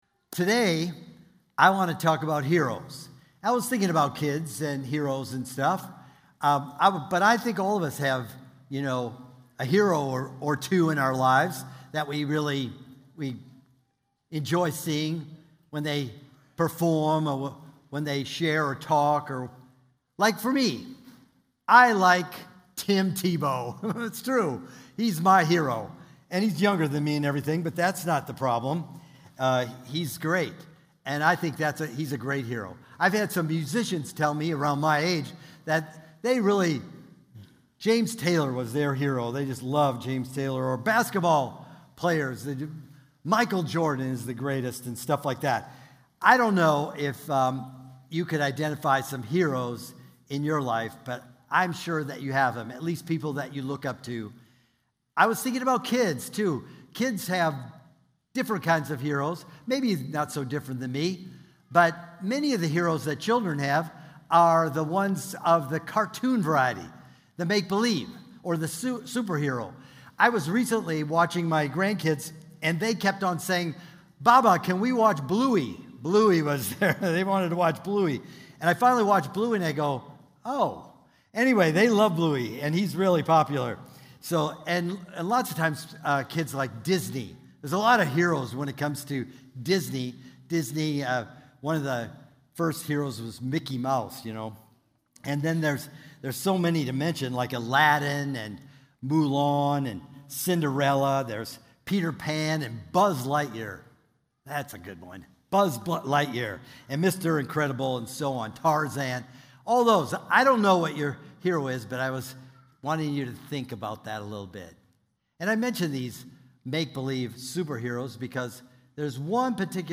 Joshua 1:1-9 (2025 Stand Alone Sermon)